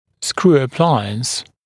[skruː ə’plaɪəns][скру: э’плайэнс]винтовой аппарат